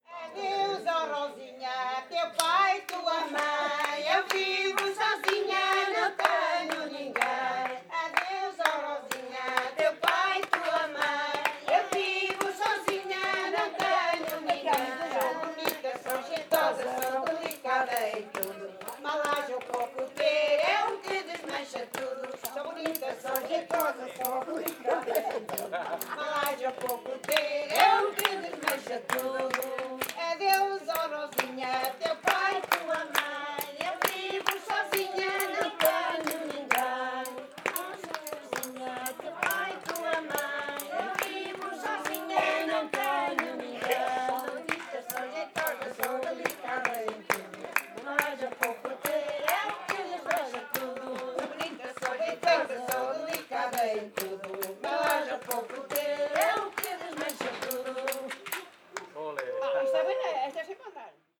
Grupo Etnográfico de Trajes e Cantares do Linho de Várzea de Calde
Adeus ó Rosinha (Várzea de Calde, Viseu)